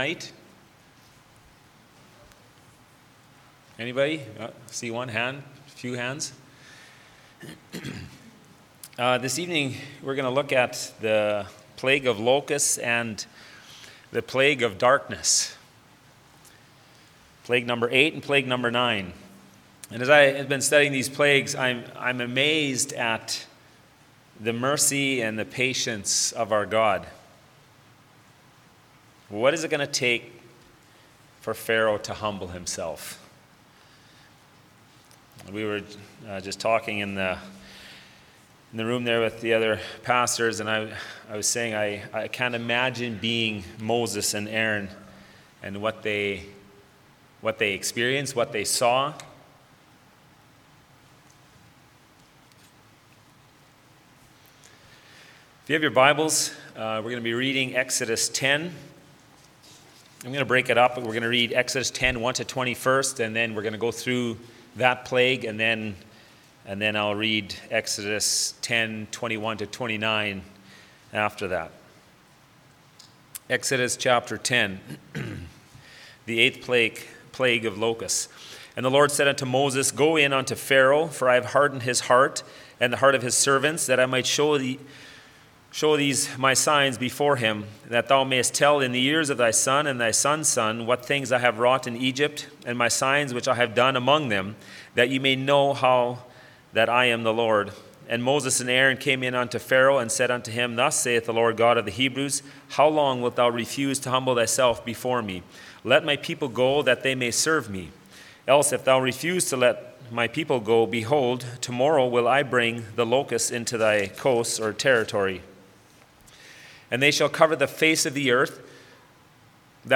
Church Bible Study – The Ten Plagues of Egypt